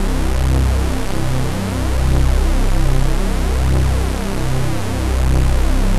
C1_jx_phat_lead_1.wav